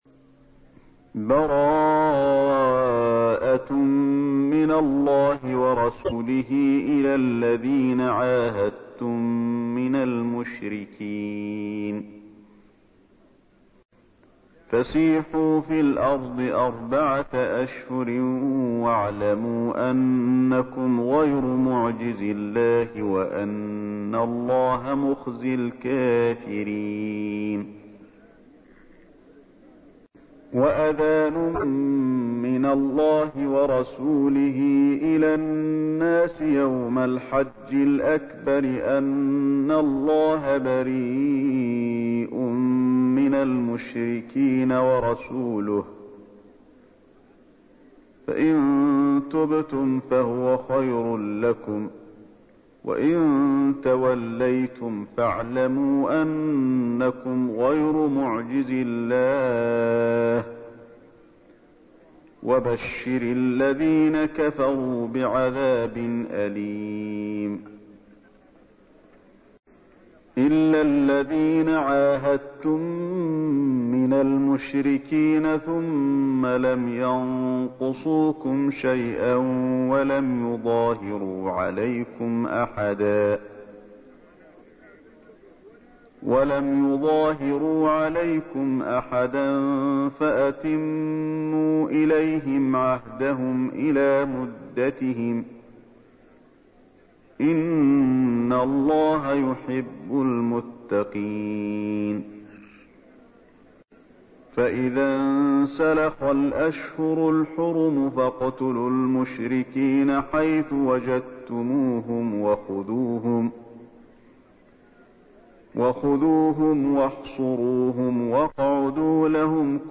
تحميل : 9. سورة التوبة / القارئ علي الحذيفي / القرآن الكريم / موقع يا حسين